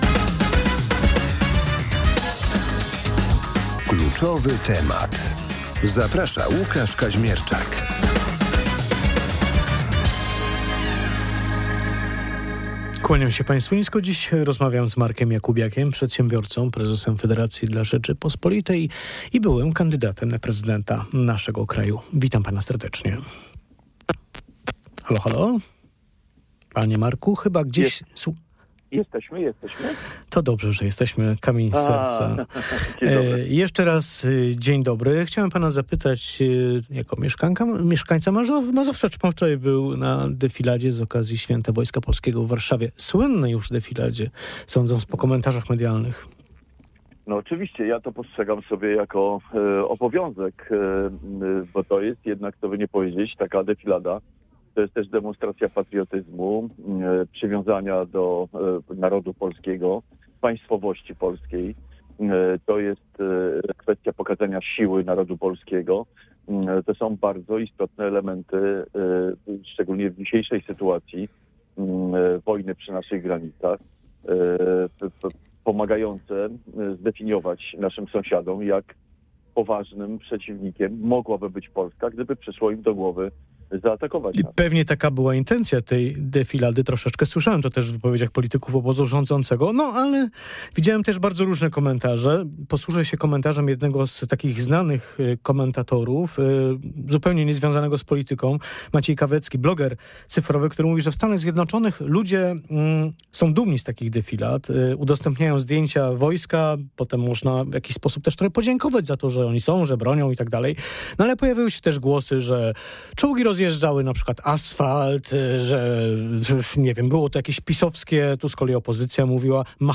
Polityk zachęcał też w porannej rozmowie Radia Poznań do udziału w referendum, które, jak zapowiadają politycy rządzącej partii, odbędzie się w dniu wyborów parlamentarnych.